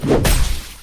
melee-hit-5.ogg